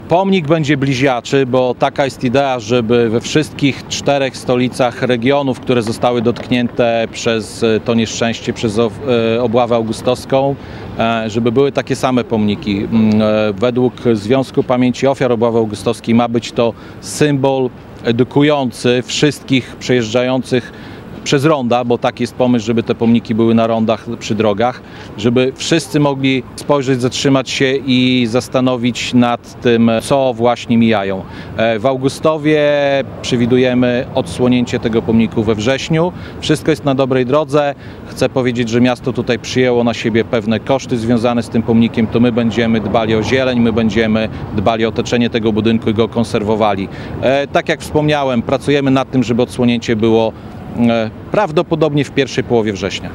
Za wykonanie pomnika zapłacą darczyńcy, a koszty przygotowania i opieki nad terenem pokryje samorząd. Szczegóły przedstawił Mirosław Karolczuk, burmistrz miasta.